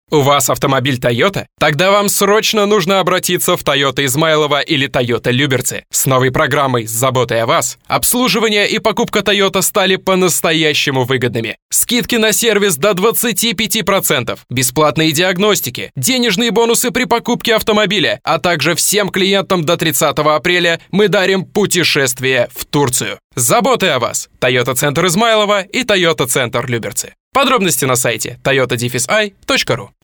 Владею широким диапазоном эмоций - все для вас, как говорится.
Blue Yeti